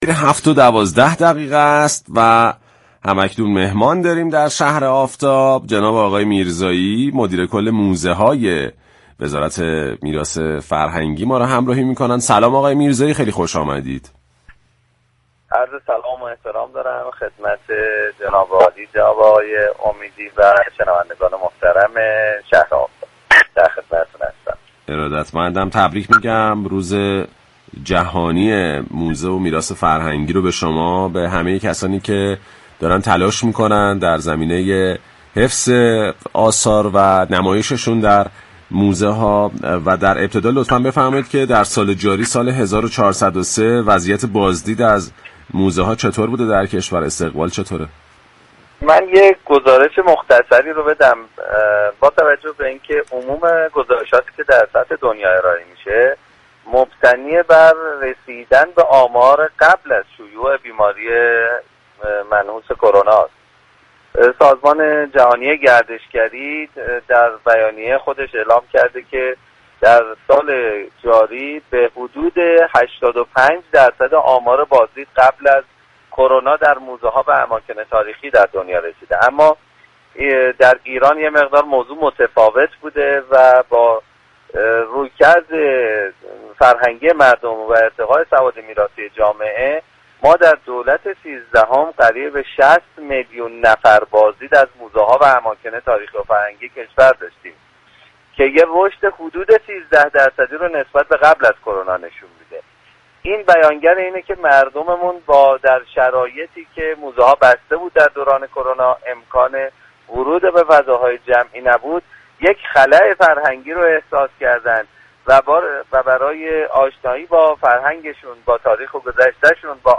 آمار بازدیدكنندگان از موزه‌ها و آثار تاریخی 13 درصد رشد داشته است به گزارش پایگاه اطلاع رسانی رادیو تهران، هادی میرزایی مدیركل موزه‌های وزارت میراث فرهنگی در گفت و گو با «شهر آفتاب» رادیو تهران اظهار داشت: در دولت سیزدهم حدود 60 میلیون نفر از موزه‌ها و اماكن تاریخی و فرهنگی كشور بازدید كردند.